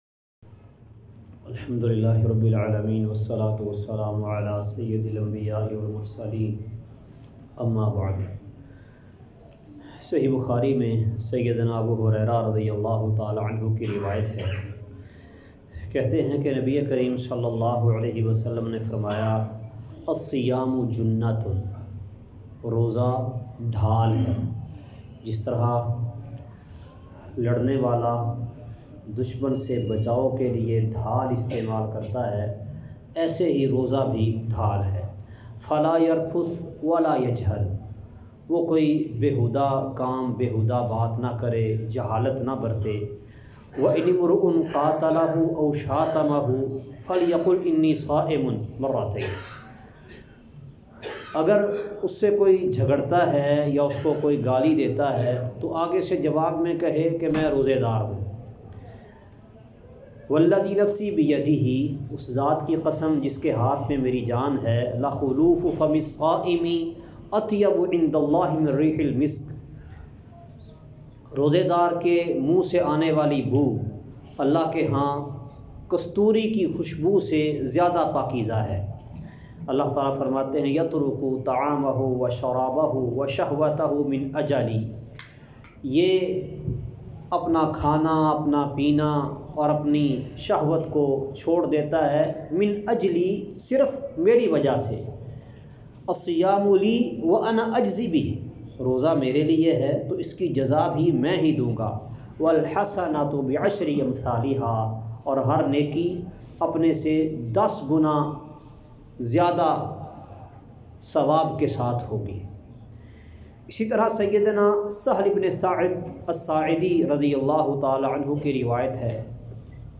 روزے کے فضائل درس کا خلاصہ روزہ اور روزدہ دار کے فضائل آڈیو فائل ڈاؤنلوڈ کریں × الحمد لله رب العالمين، والصلاة والسلام على سيد الأنبياء والمرسلين، أما بعد!